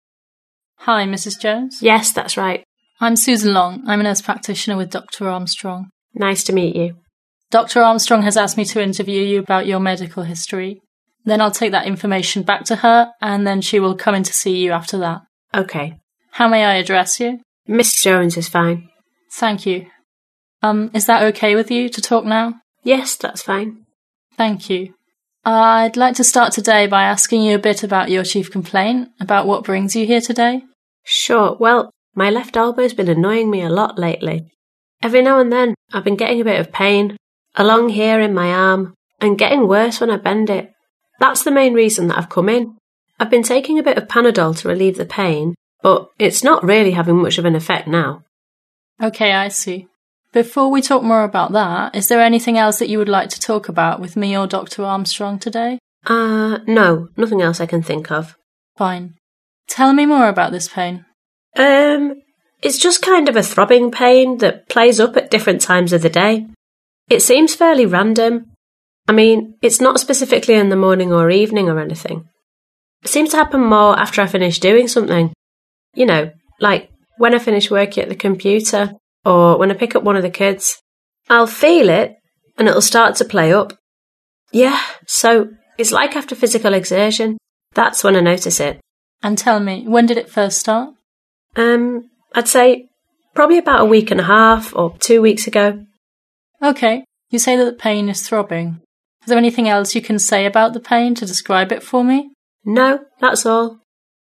4. Listening: Taking medical histories.